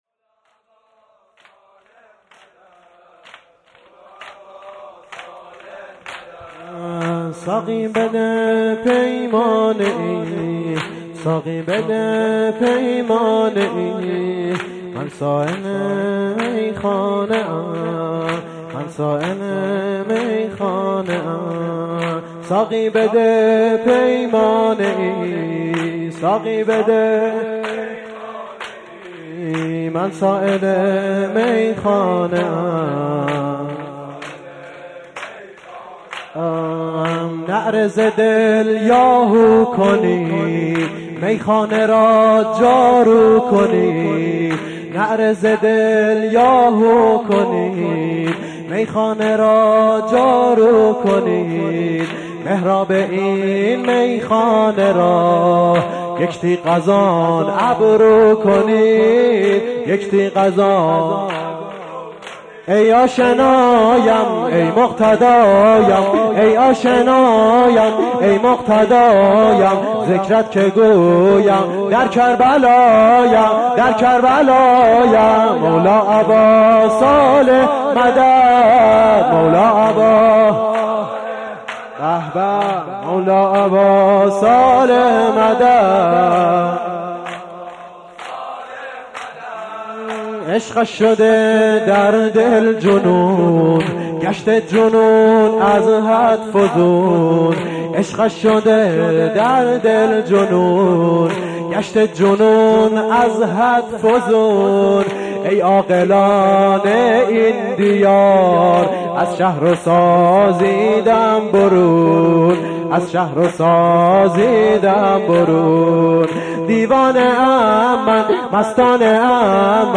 شهادت حضرت عبدالعظیم و حمزه سیدالشهدا علیهم السلام - تک